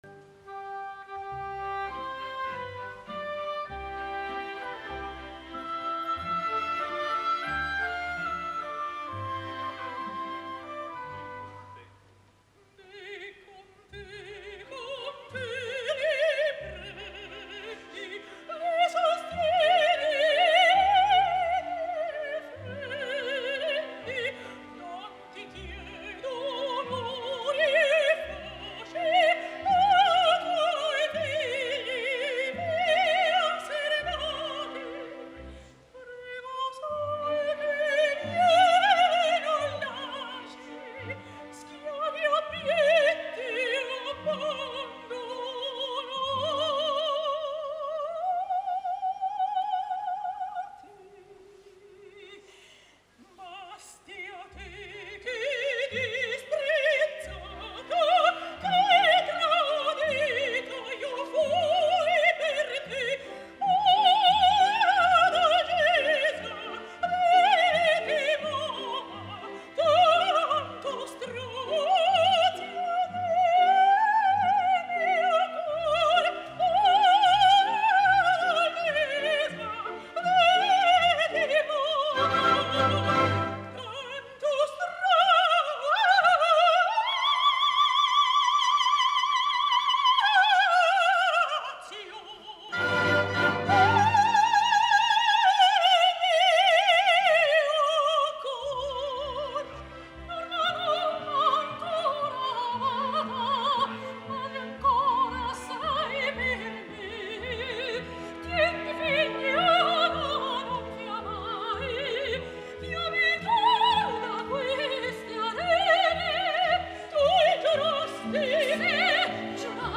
veterana soprano
repertori del belcanto italià
La cita va tenir lloc al Teatro Comunale de Bologna el passat mes d’abril i avui us porto la gravació de la representació del dia 13 d’abril de 2013 que es la que ha retransmès la RAI, que beneficia bastant a la fins ara disponible, de procedència “desconeguda”.